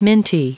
Prononciation du mot minty en anglais (fichier audio)
Prononciation du mot : minty